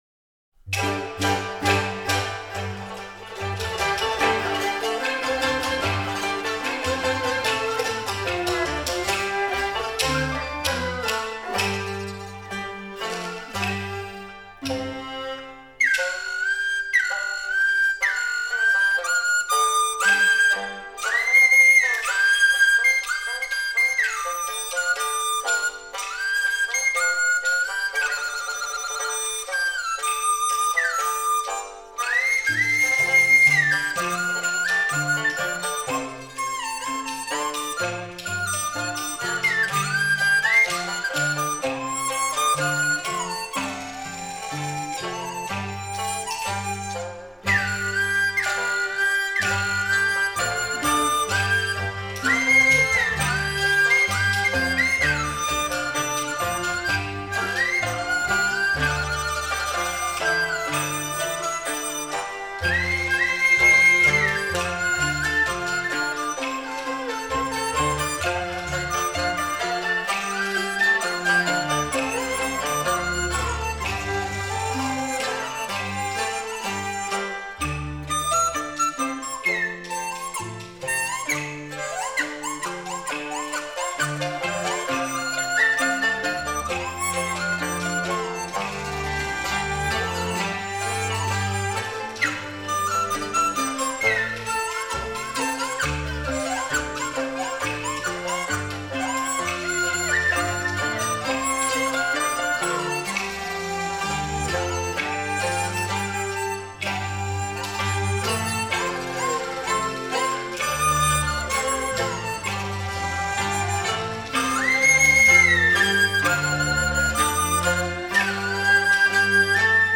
中国吹管乐
梆笛
此曲原为二人台音乐，旋律优美、活泼，表现人民欢庆节日时，红灯高挂张灯结彩的欢腾火热气氛。
[二]、[三]是第一段的变奏，速度逐渐加快，气氛渐次热烈。